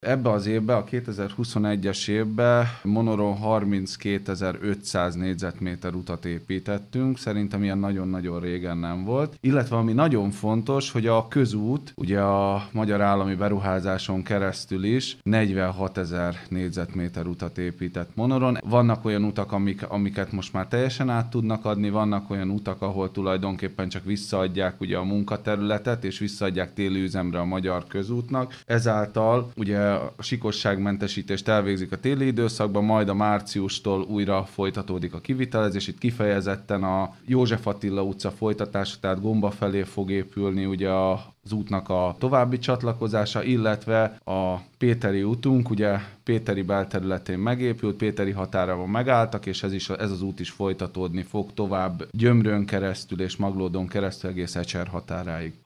Darázsi Kálmán polgármestert hallják.